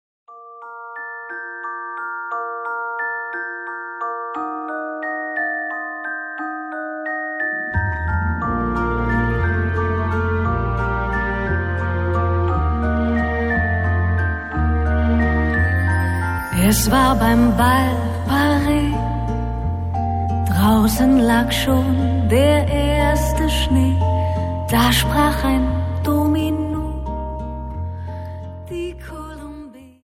Dance: Viennese Wltz